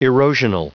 Prononciation du mot erosional en anglais (fichier audio)
Prononciation du mot : erosional